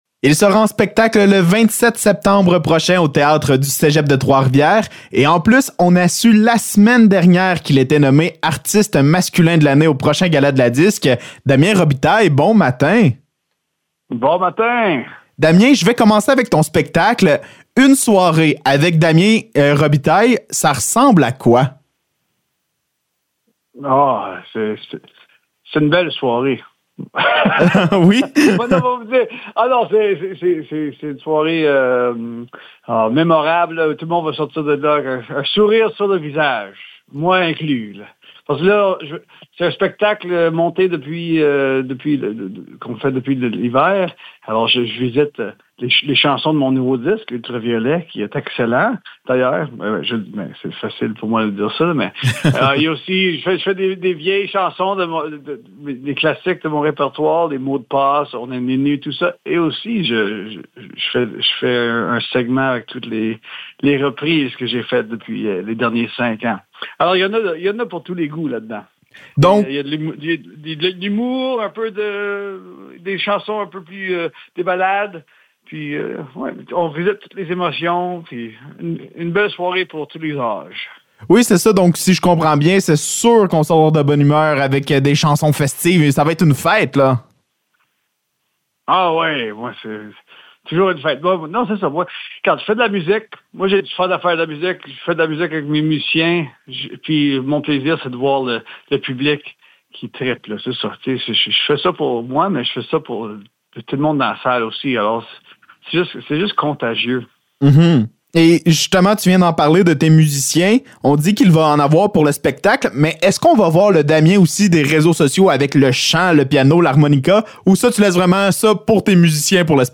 Entrevue avec Damien Robitaille
Entrevue avec Damien Robitaille concernant son passage à Trois-Rivières le 27 septembre prochain et de ses nominations au prochain Gala de l’ADISQ.